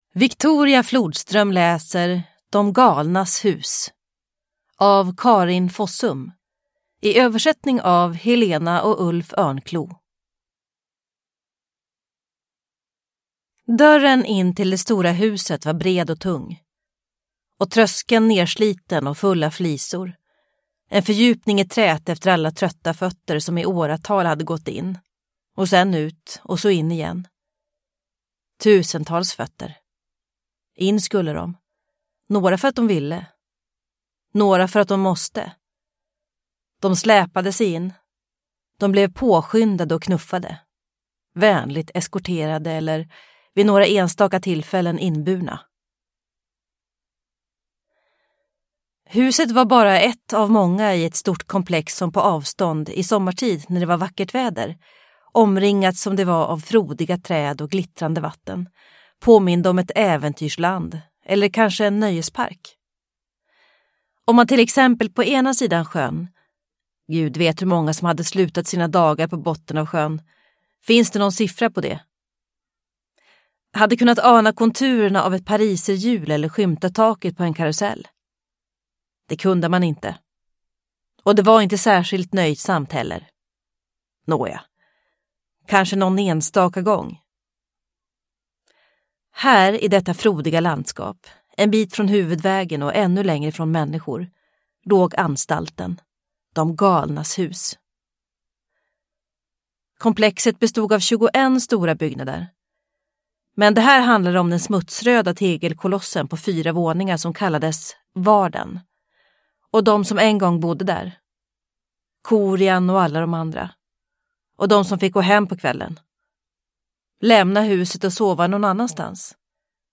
De galnas hus – Ljudbok – Laddas ner